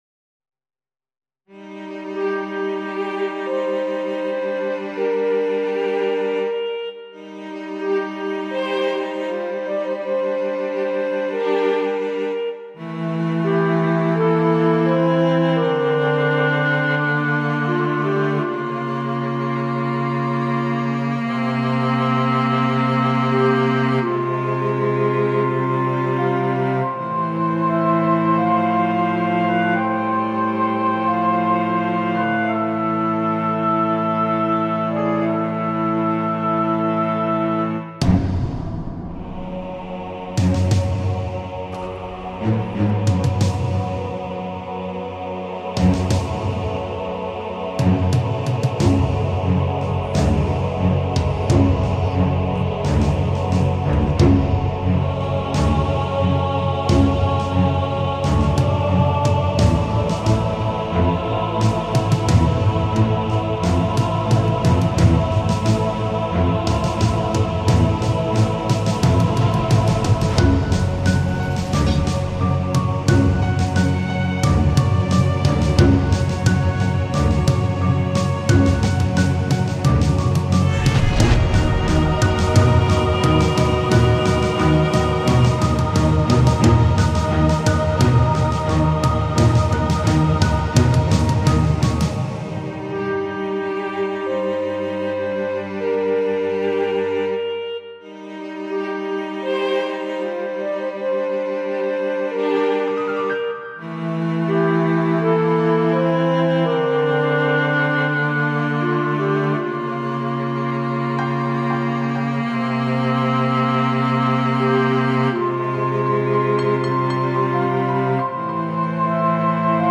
Presto [170-180] colere - violon - guerre - soldat - mort